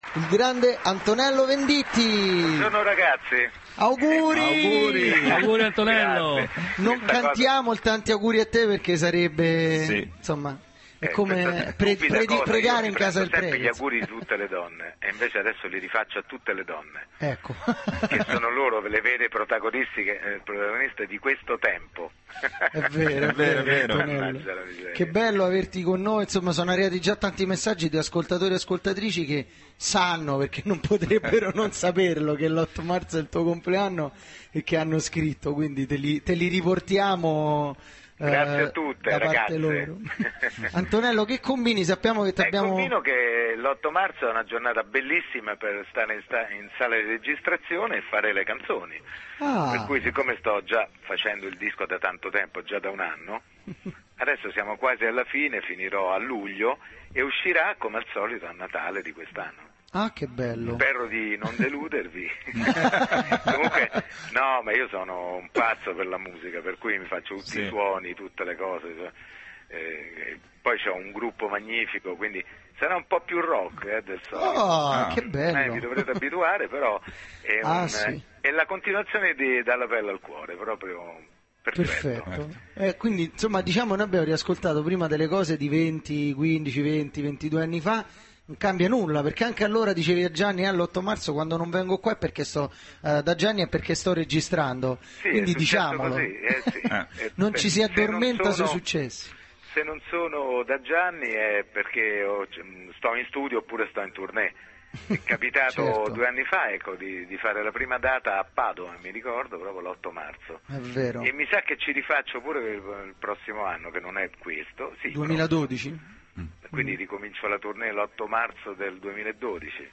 Intervento telefonico Antonello Venditti del 08/03/2011